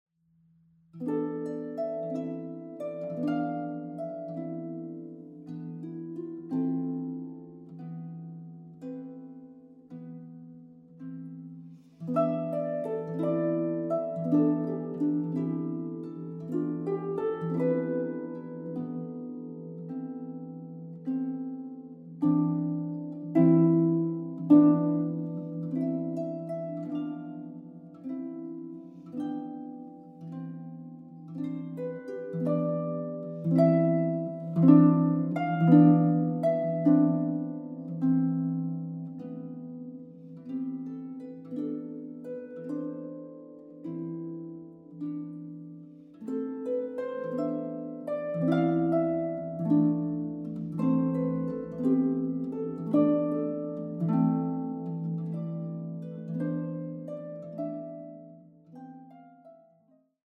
Zeitgenössische Musik für Harfe